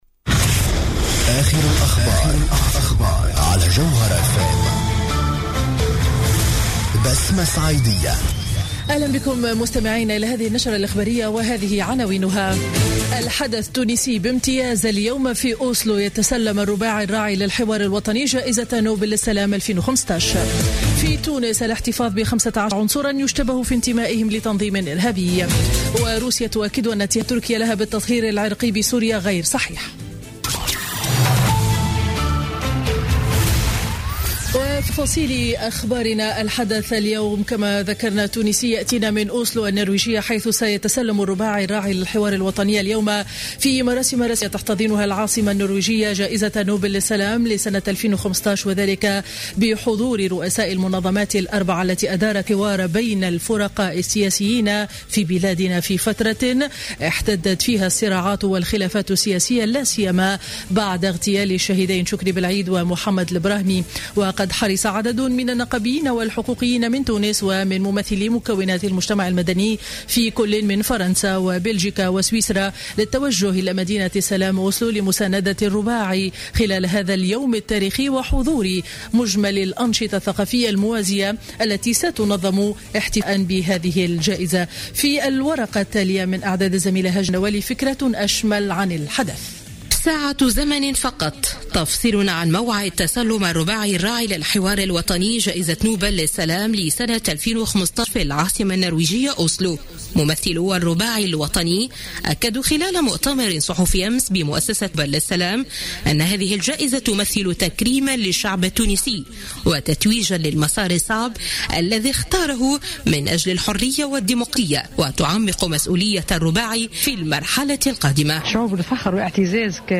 نشرة أخبار منتصف النهار ليوم الخميس 10 ديسمبر 2015